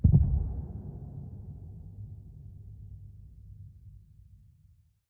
Minecraft Version Minecraft Version snapshot Latest Release | Latest Snapshot snapshot / assets / minecraft / sounds / mob / warden / nearby_close_4.ogg Compare With Compare With Latest Release | Latest Snapshot